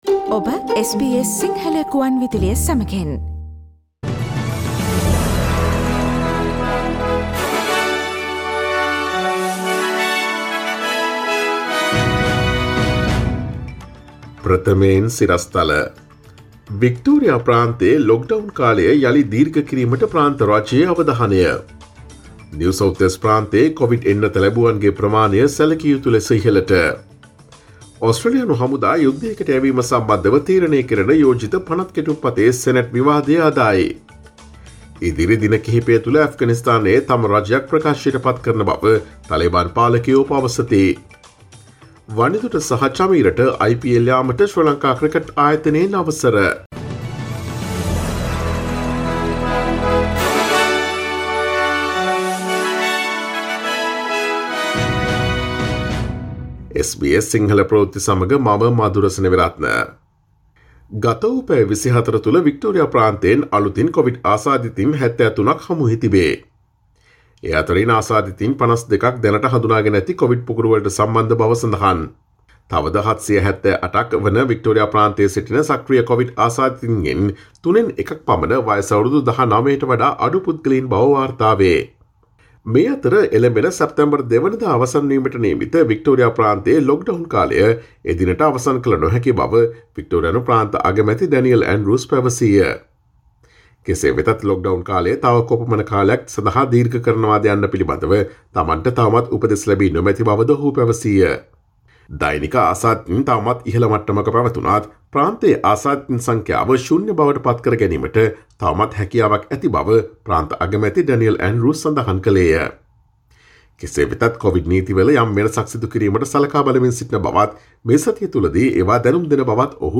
අගෝස්තු 30 දා SBS සිංහල ප්‍රවෘත්ති: NSW කොවිඩ් එන්නත ලැබූවන්ගේ ප්‍රමාණය දෛනිකව සැලකිය යුතු ලෙස ඉහළට